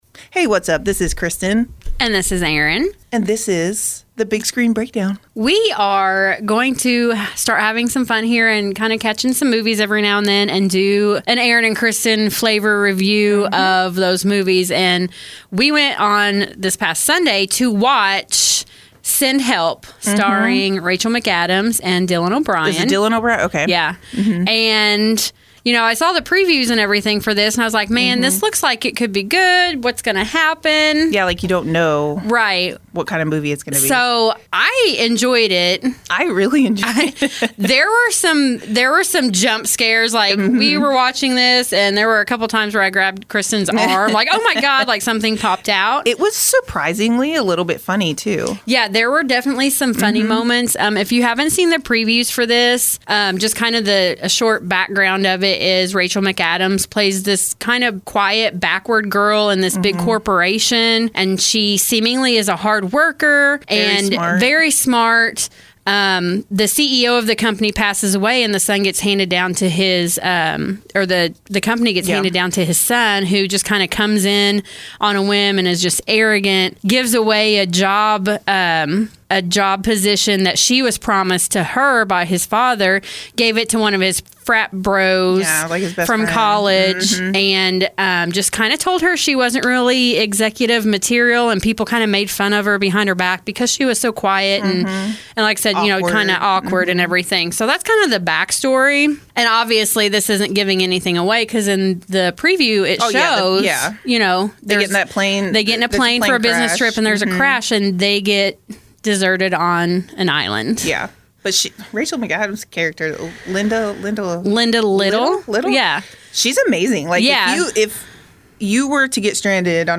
The Big Screen Breakdown brings you our thoughts, reactions, and reviews. With different personalities, honest opinions, and plenty of laughs along the way, The Big Screen Breakdown delivers real reactions to the latest movies and what’s actually worth watching.